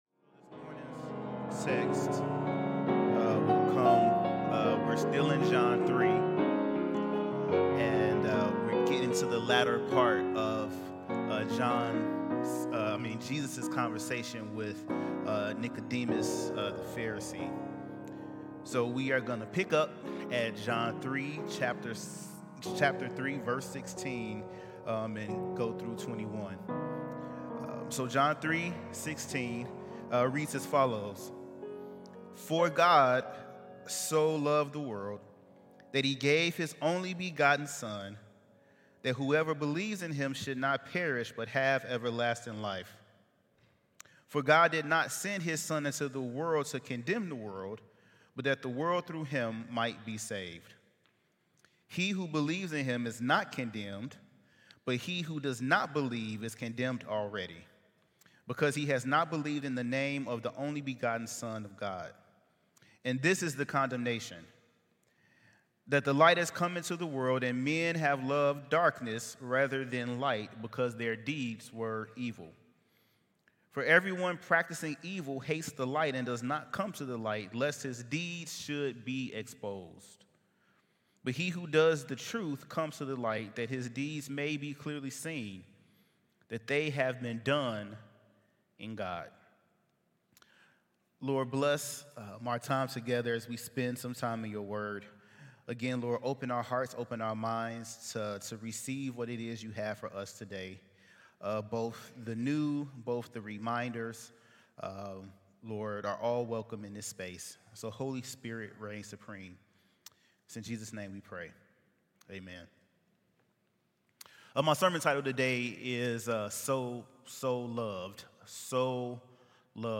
Sermon So Loved November 23